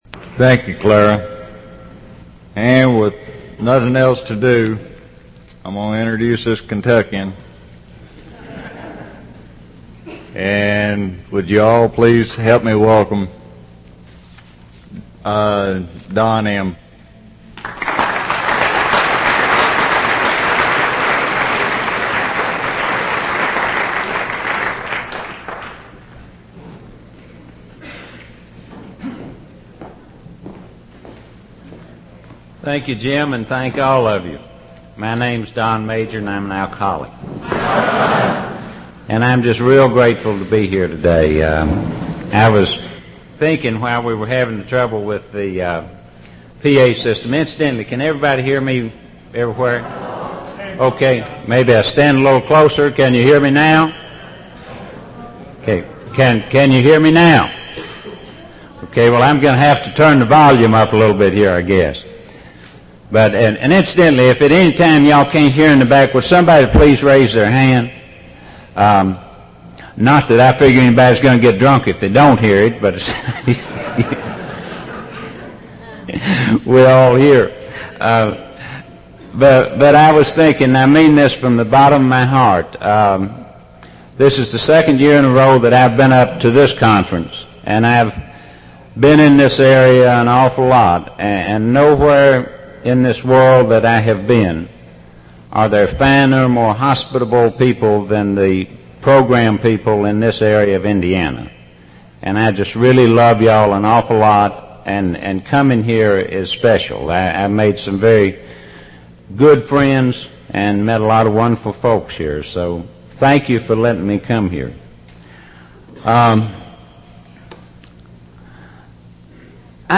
West Central Indiana Mini Conference' Dansville IN - 1990 | AA Speakers